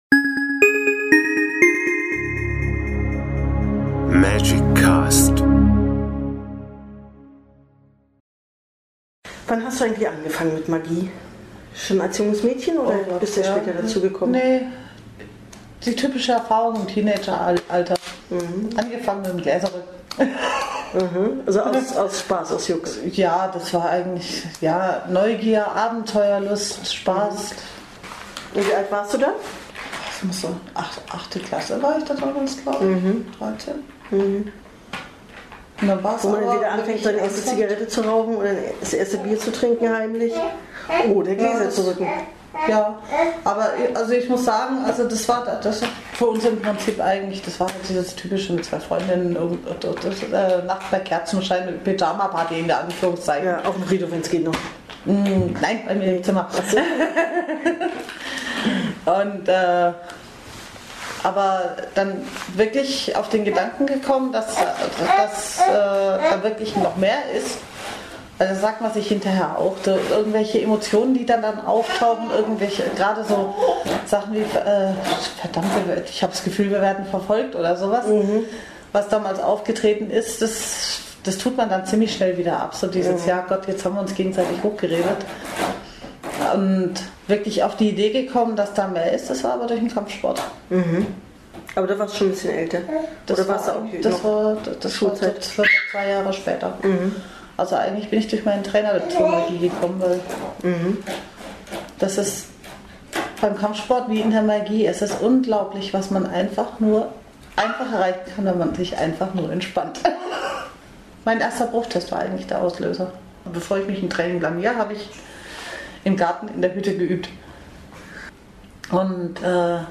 Tassengeklimper, Babygebrabbel und Tortenfiasko inklusive.